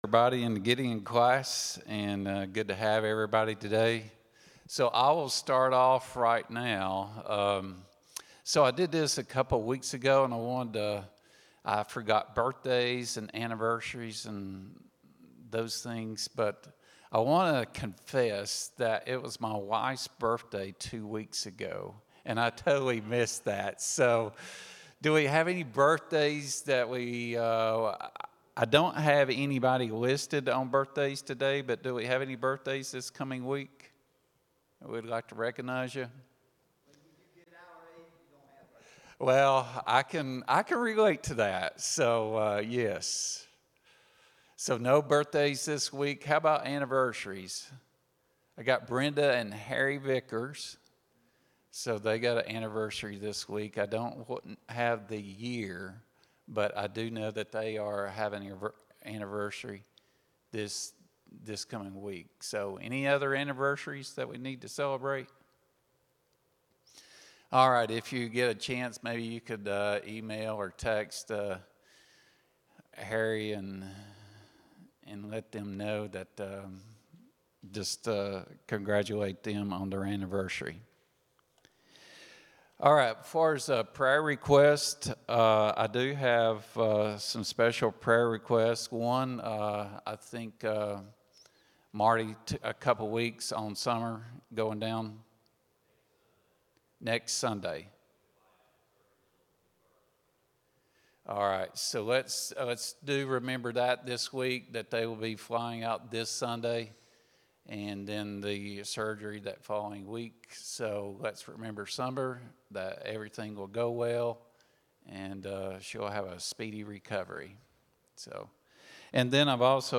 10-12-25 Sunday School | Buffalo Ridge Baptist Church